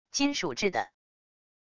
金属制的wav音频